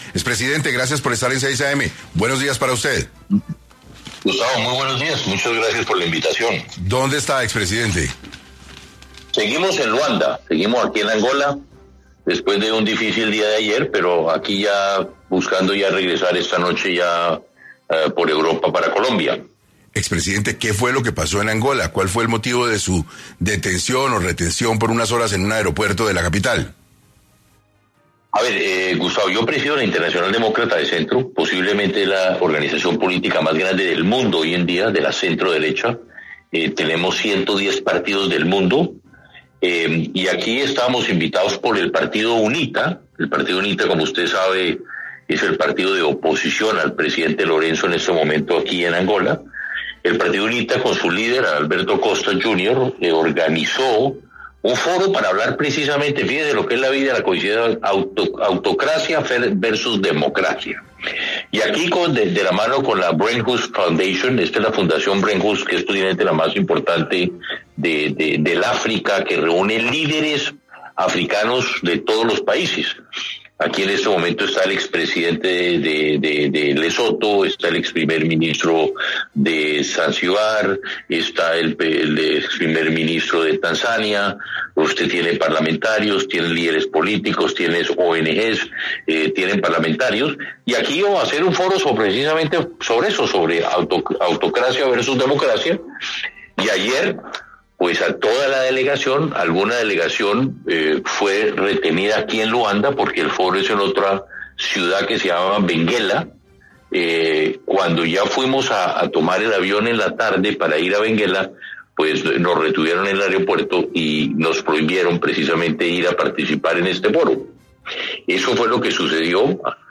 En 6AM de Caracol Radio estuvo el expresidente de la República, Andrés Pastrana, para hablar sobre lo sucedido en el aeropuerto de Angola y por qué lo retuvieron.